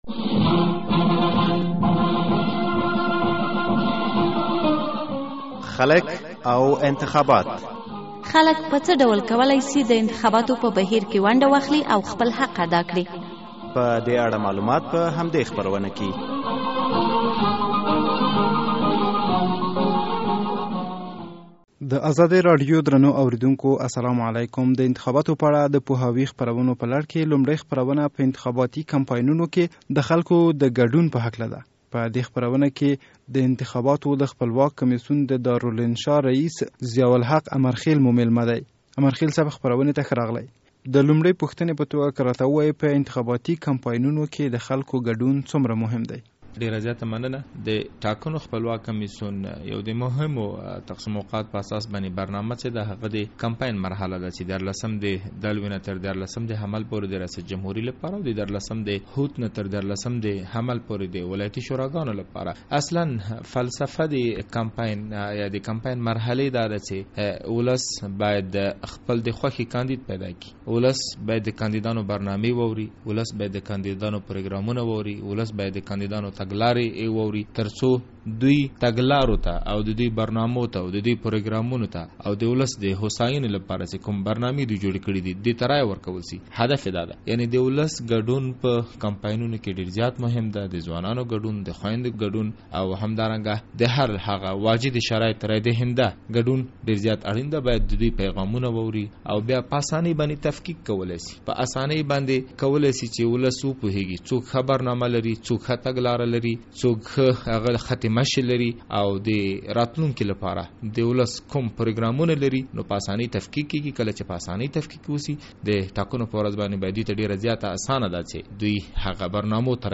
د خپلواک کمیسیون د دارالانشا رییس ضیاالحق امرخیل سره مرکه